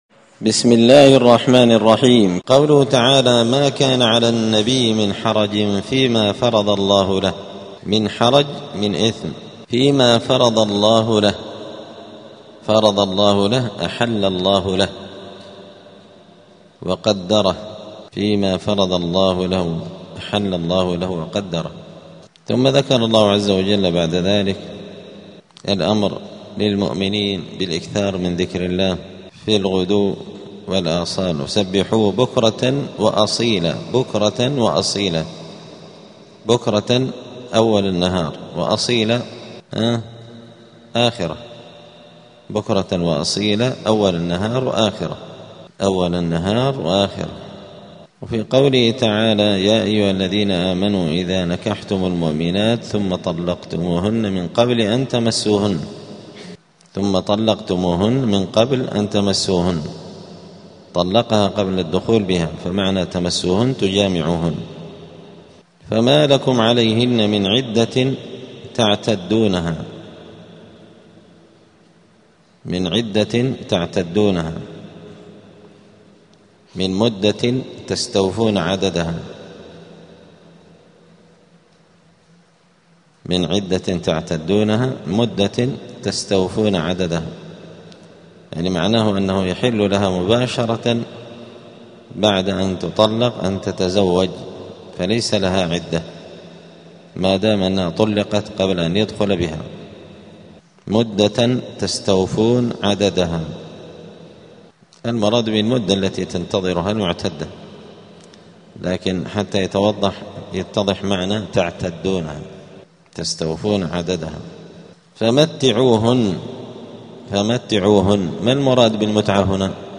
*(جزء وسورة الأحزاب الدرس 322)*
دار الحديث السلفية بمسجد الفرقان قشن المهرة اليمن